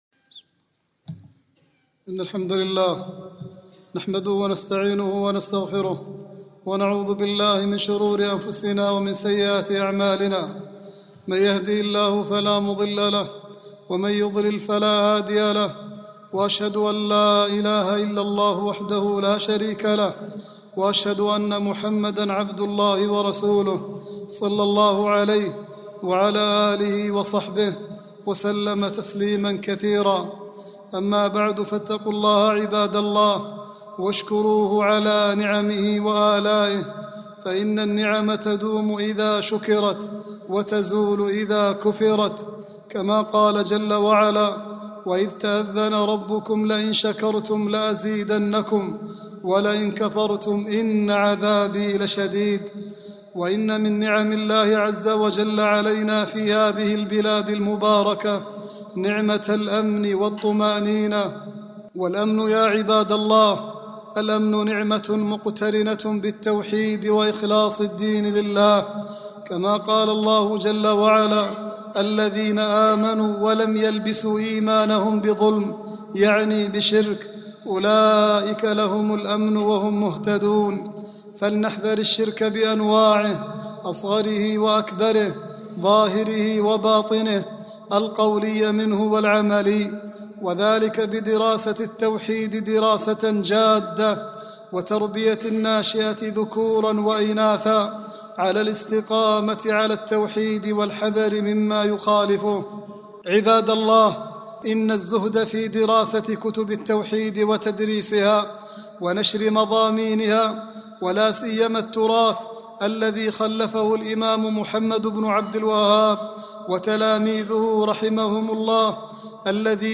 العنوان : نعمة الأمن والرخاء والأخوة الدينية والحث على شكرها خطبة
khutbah-4-4-39.mp3